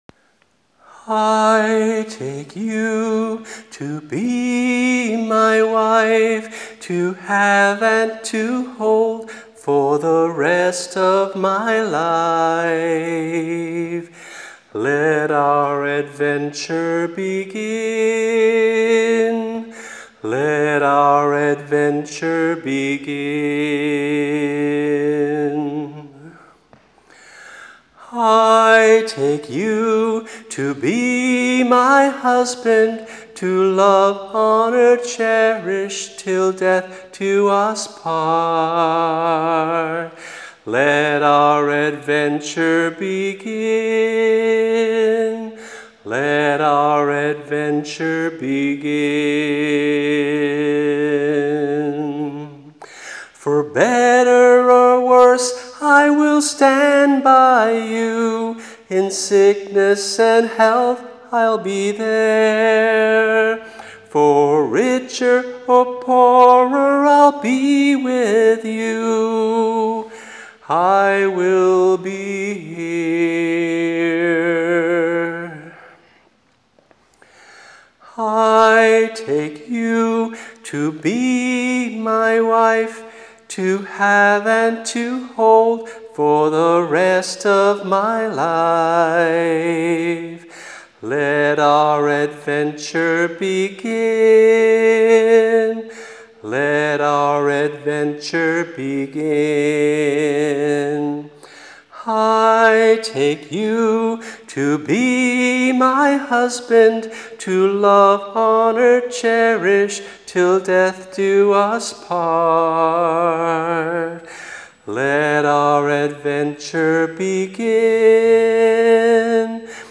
(a wedding song)
MP3 (just vocal)